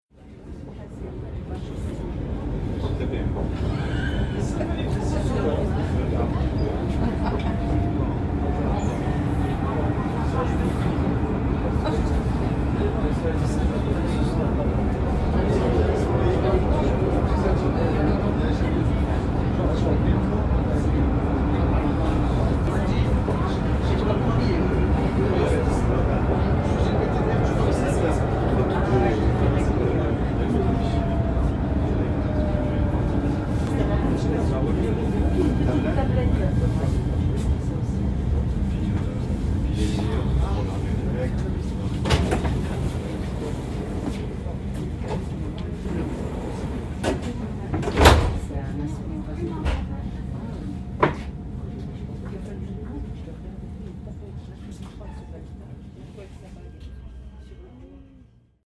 LAEihA77zkb_tram.mp3